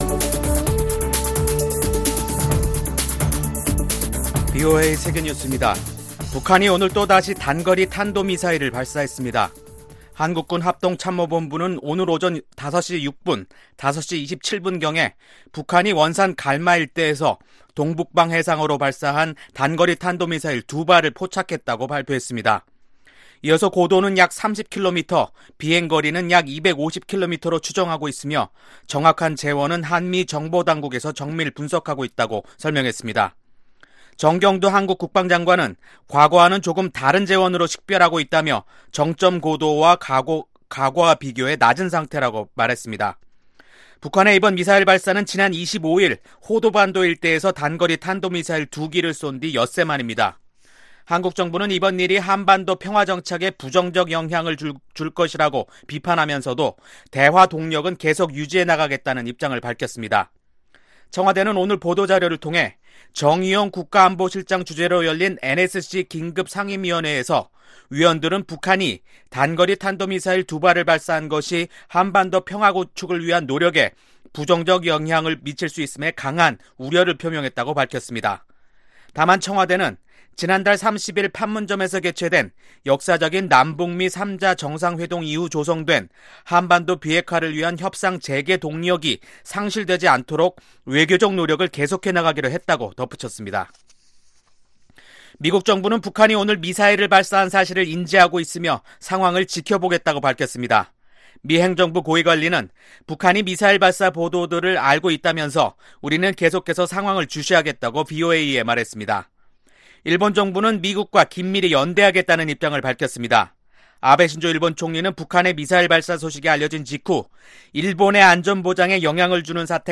VOA 한국어 간판 뉴스 프로그램 '뉴스 투데이', 2019년 7월 31일 2부 방송입니다. 북한이 엿새 만에 또 다시 단거리 탄도미사일을 발사했습니다. 미국 정부 관계자들이 지난주 판문점에서 북한 당국자들과 만나 실무 협상 재개 의사를 전달 받은 것으로 확인됐습니다.